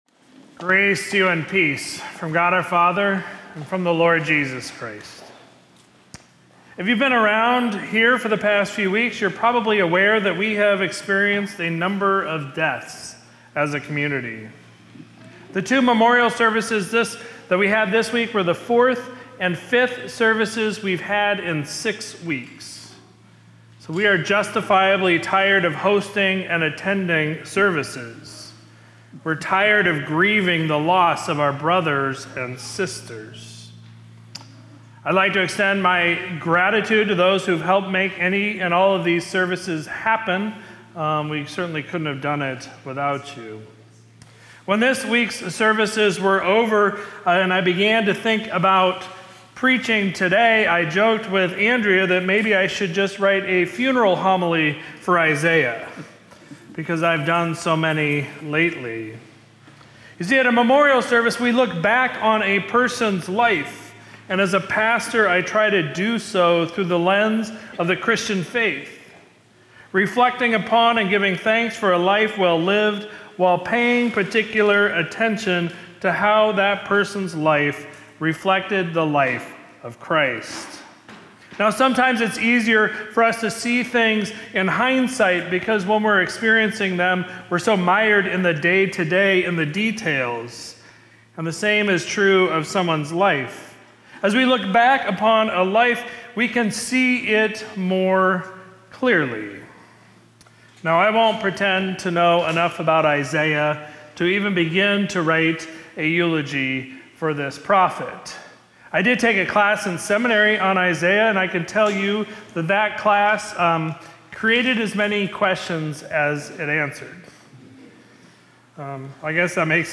Sermon from Sunday, November 17, 2024